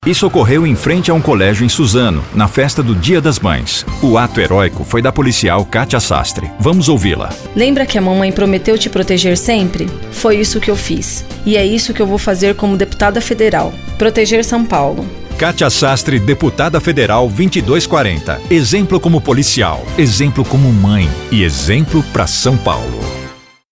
Locução off para vídeo de propaganda eleitoral para a policial Kátia Sastre, Deputada Federal 2240 - PR em parceria com o estúdio Lord G